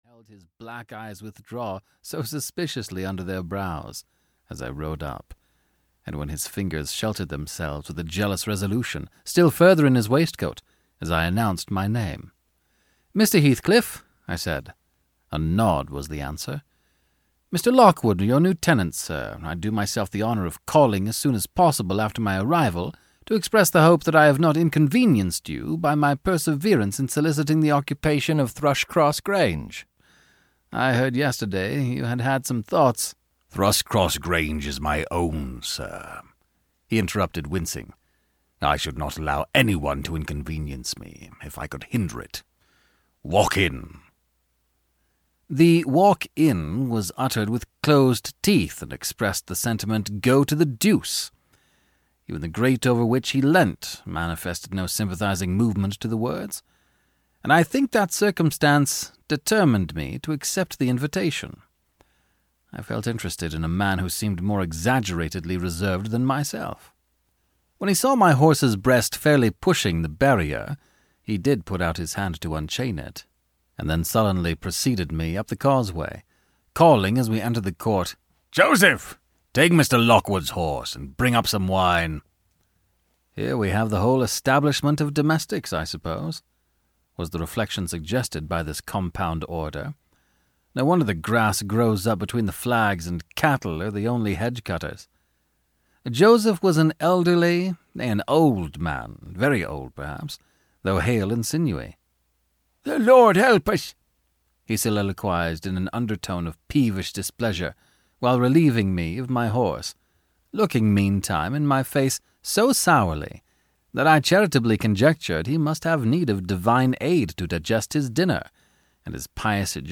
Wuthering Heights (EN) audiokniha
Ukázka z knihy